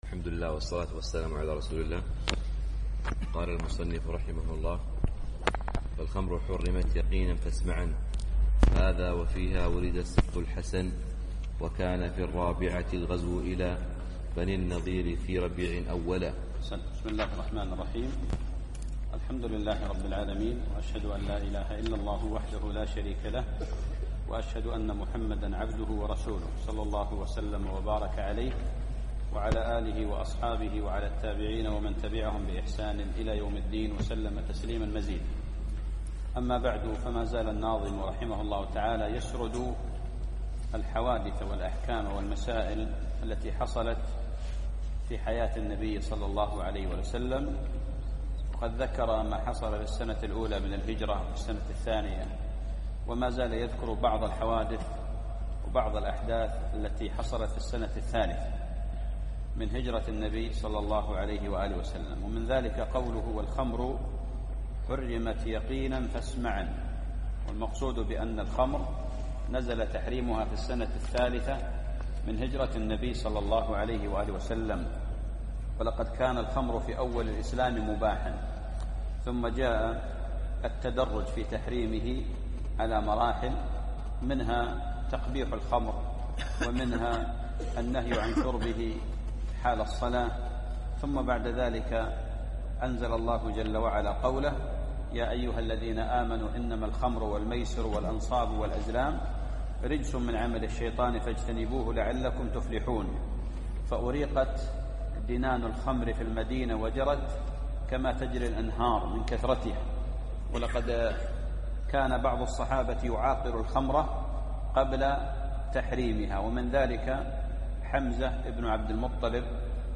الدرس التاسع